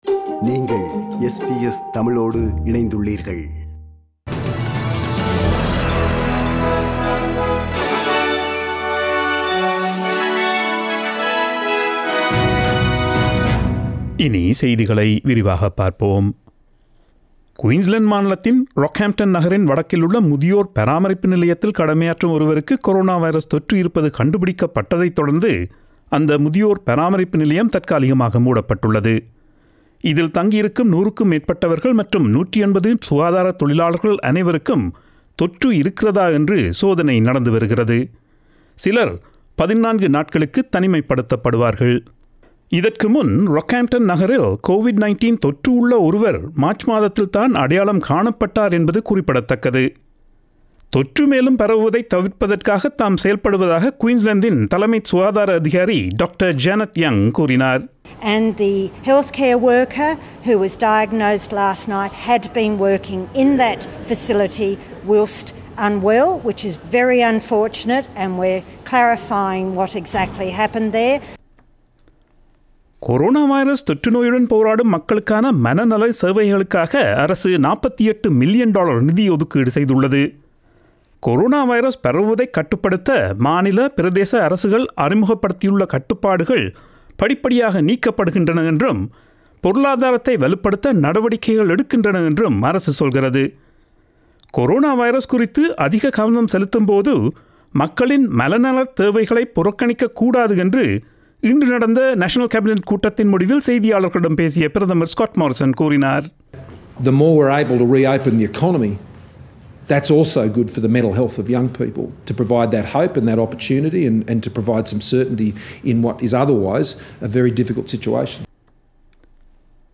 Australian news bulletin aired on Friday 15 May 2020 at 8pm.